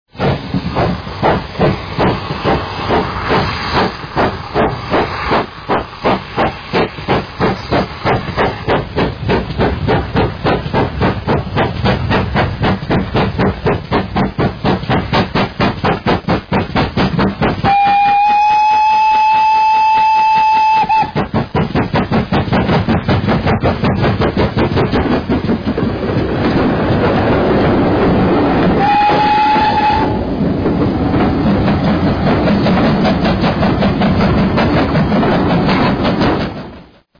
Sounds of Great Western steam locomotives